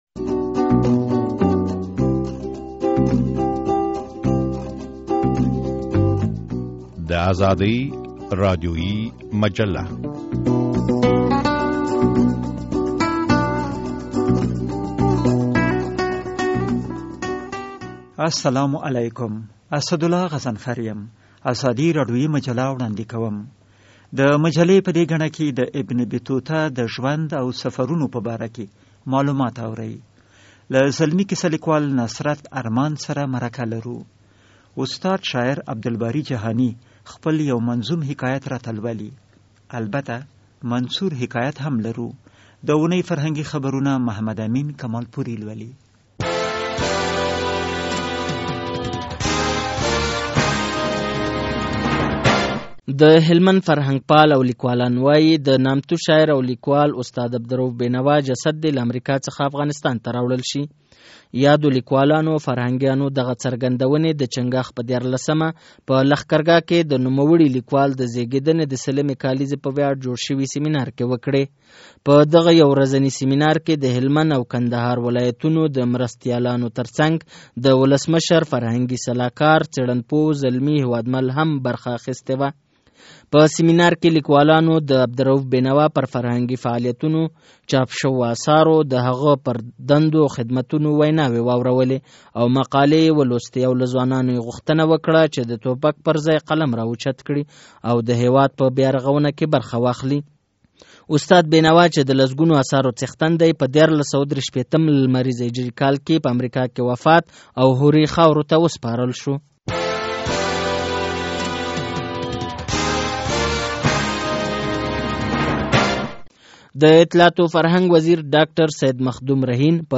عبدالباري جهاني خپل یو منظوم حکایت راته لولي